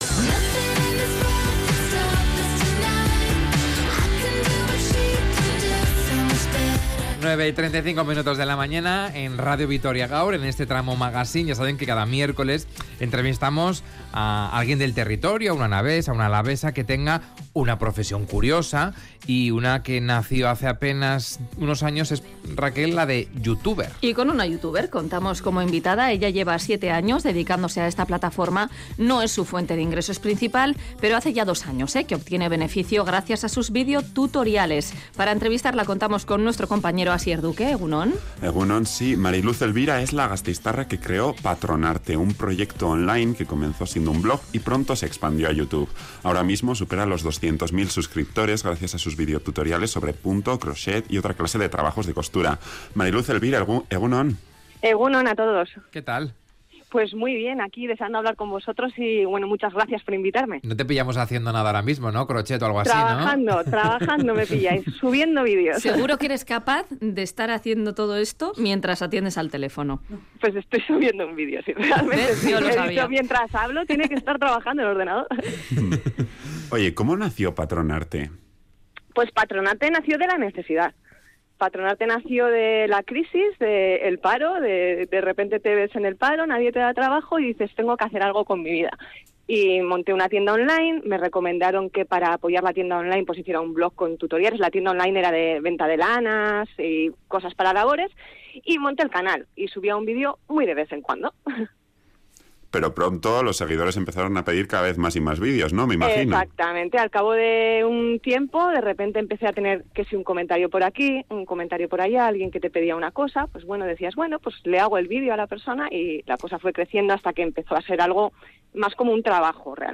Audio: Su canal cuenta ya con más de 250.000 suscriptores, y en Radio Vitoria Gaur la entrevistamos para conocer cómo es su labor produciendo sus videotutoriales